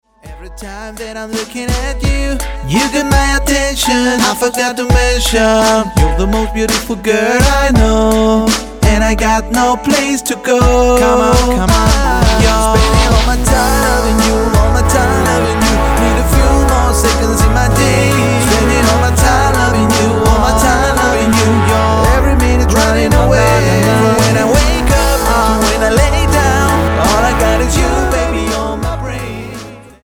NOTE: Vocal Tracks 1 Thru 9